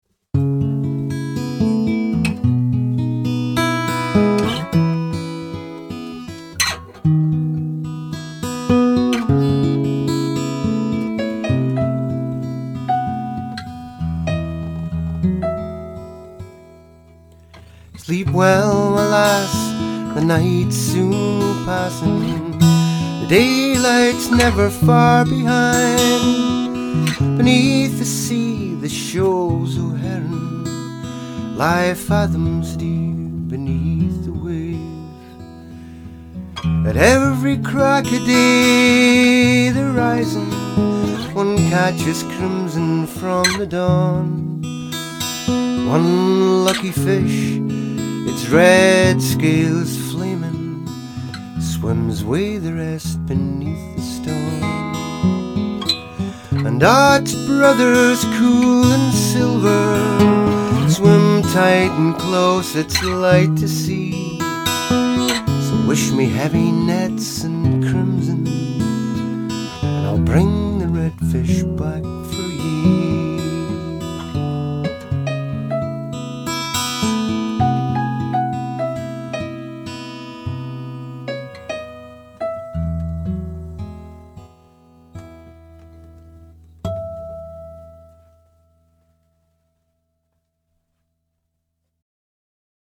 contemporary Scottish folk songs